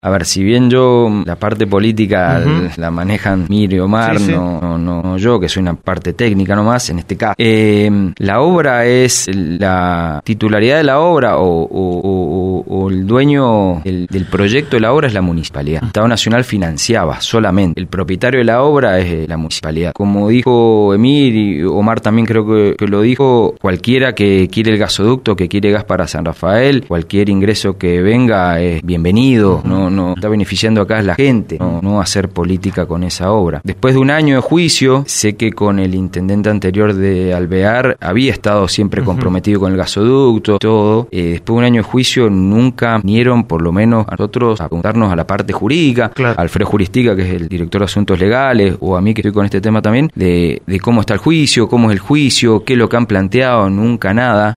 al aire de LV18